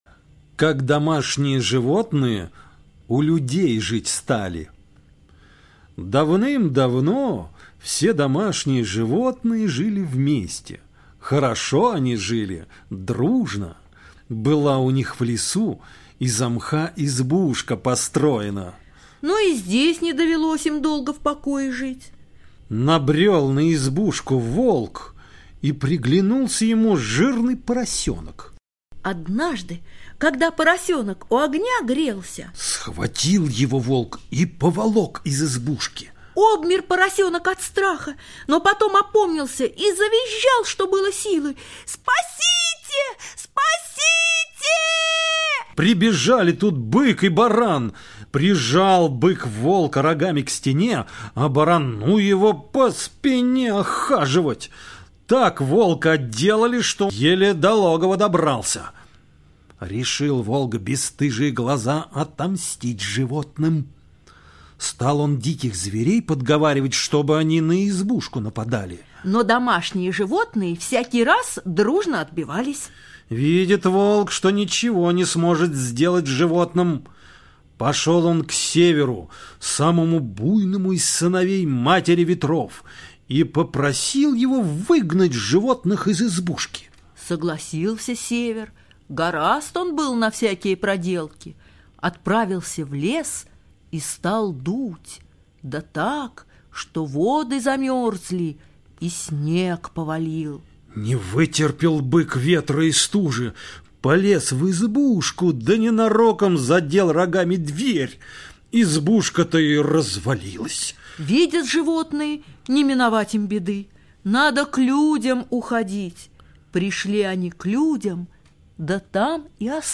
Как домашние животные у людей жить стали – латышская аудиосказка